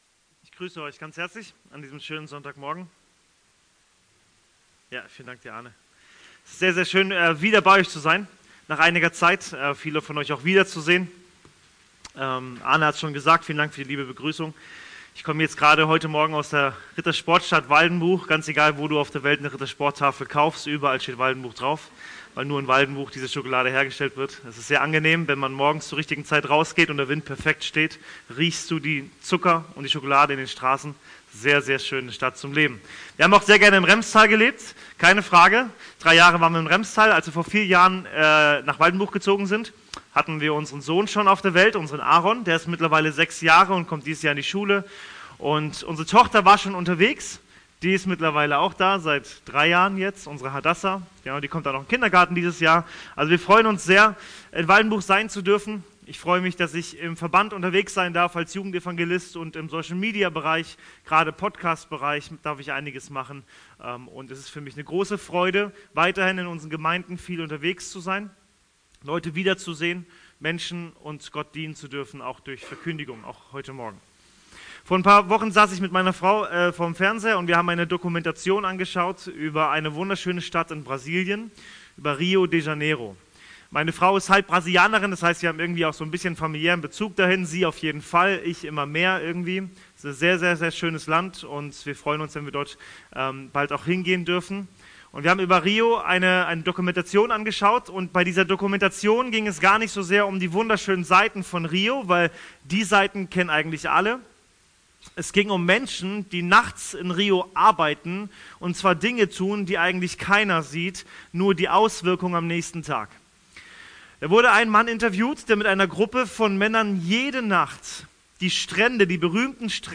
Predigten aus der Fuggi